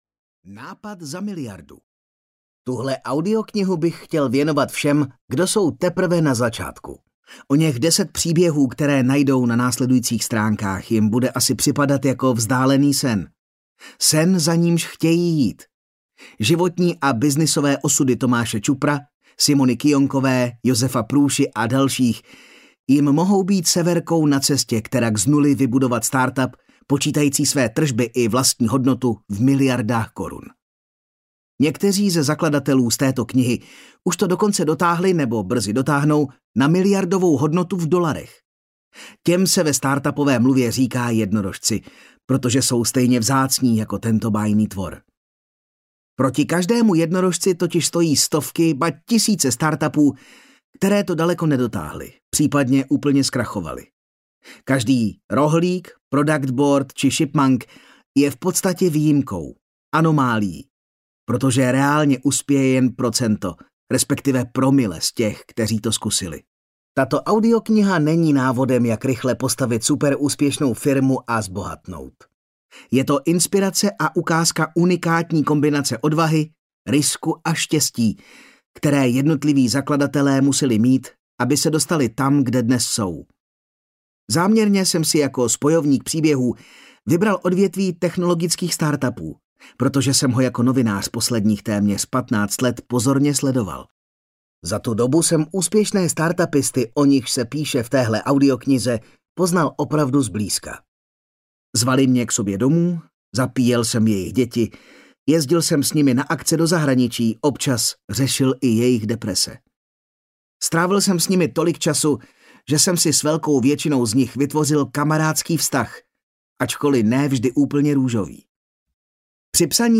Nápad za miliardu audiokniha
Ukázka z knihy